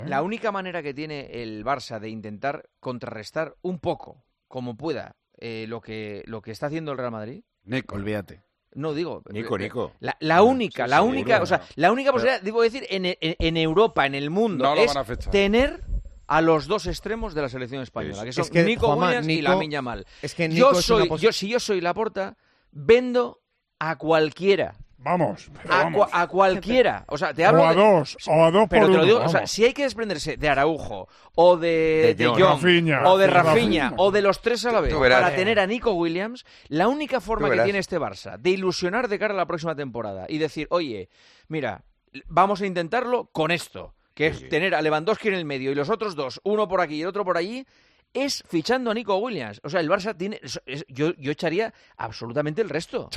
Anoche, en una nueva entrega de El Tertulión de los domingos, Juanma Castaño habló de la figura del jugador del Athletic y de lo que haría él si fuera el presidente del Barcelona, Joan Laporta, "para contrarrestar al Real Madrid".